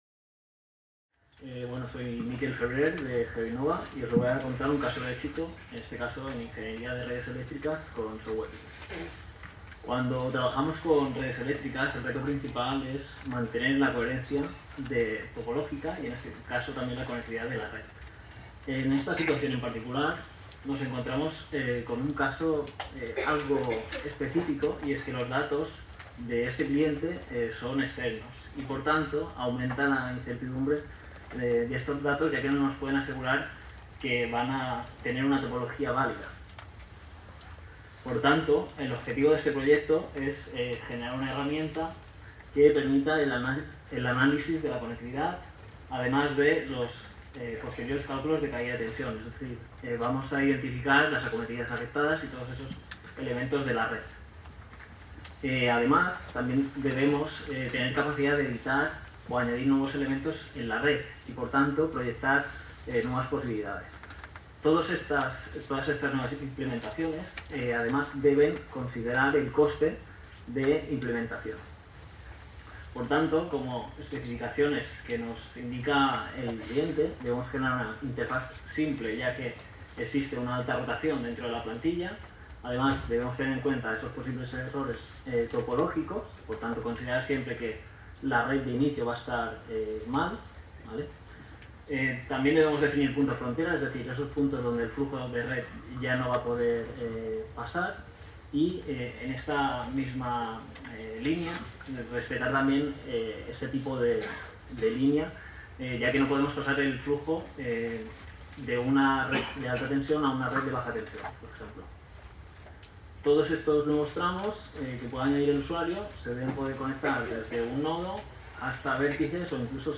Comunicació
en el marc de les 18enes Jornades de SIG Lliure 2025 organitzades pel SIGTE de la Universitat de Girona. Ens presenta casos d'èxit en la gestió de xarxes elèctriques gràcies a la utilització de software lliure com QGIS, PostgreSQL/PostGIS i altres eines de l'entorn geoespacial